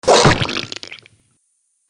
دانلود آهنگ دعوا 47 از افکت صوتی انسان و موجودات زنده
دانلود صدای دعوا 47 از ساعد نیوز با لینک مستقیم و کیفیت بالا
جلوه های صوتی